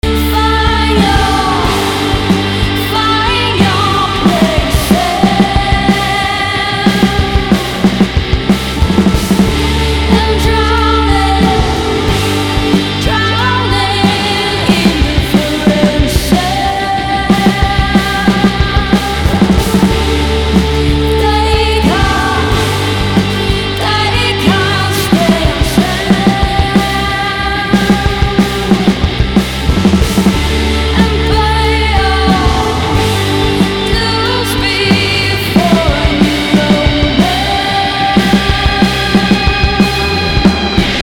a Macedonian pop rock indie jazz folk supergroup
Lead Vocal
Bass
Saxophone
Drums